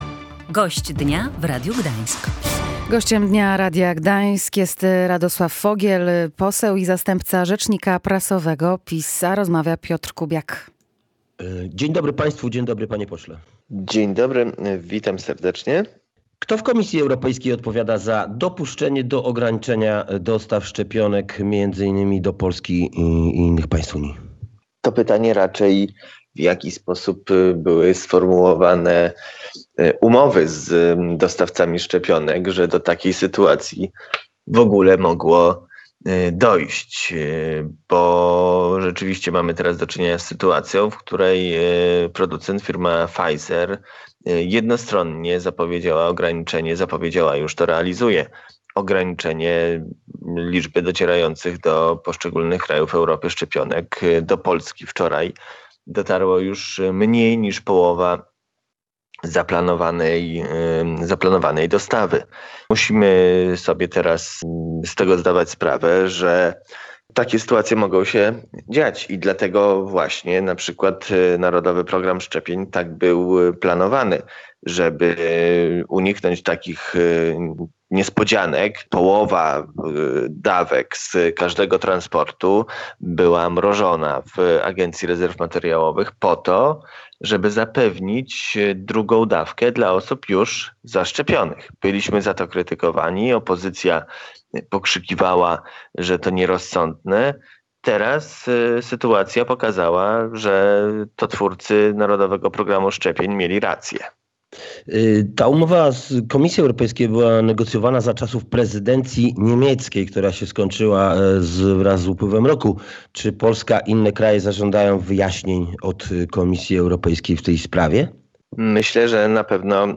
a był nim Radosław Fogiel, zastępca rzecznika prasowego PiS.